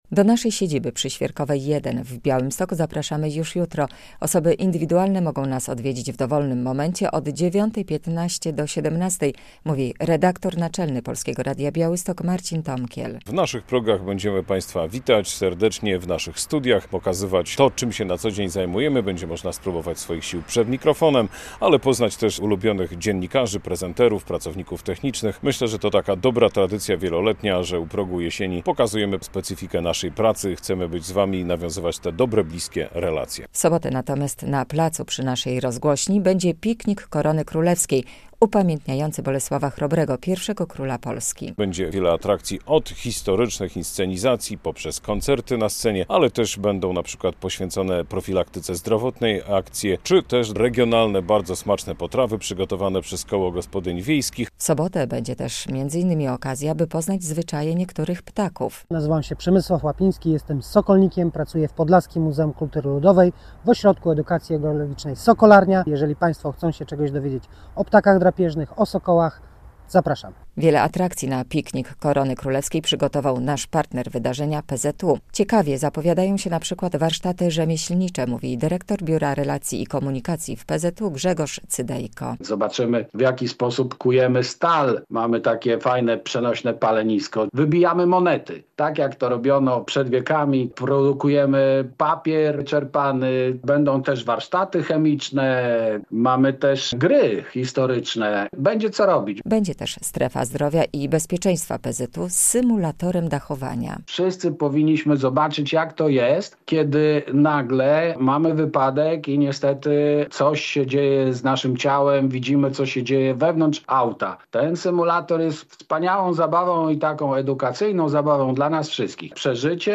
Dni Otwarte w Polskim Radiu Białystok - relacja